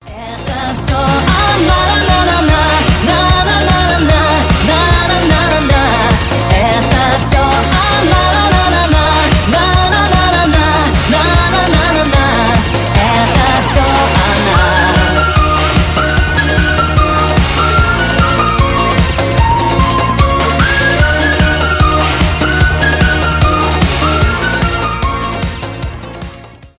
поп , ремиксы